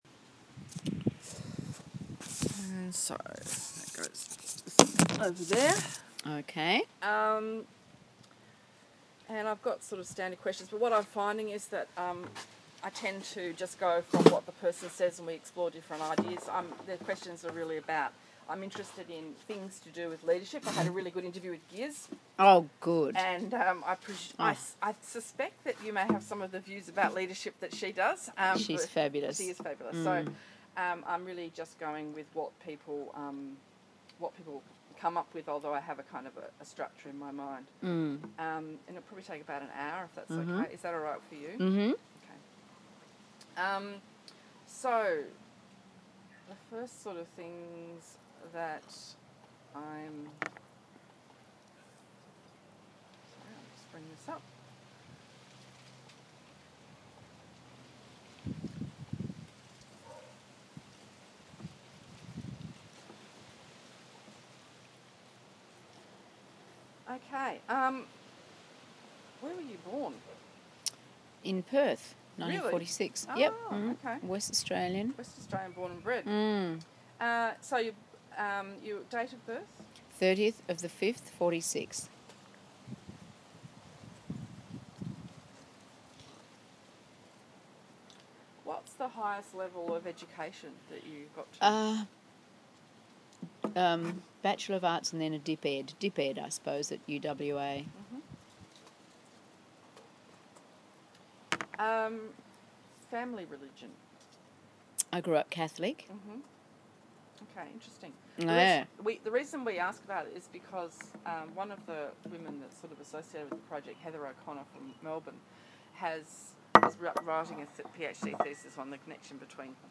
This interview was conducted as part of the ‘Women in Leadership’ project in 2011.